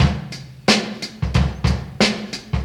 • 90 Bpm Drum Loop Sample D Key.wav
Free breakbeat sample - kick tuned to the D note. Loudest frequency: 1600Hz
90-bpm-drum-loop-sample-d-key-USd.wav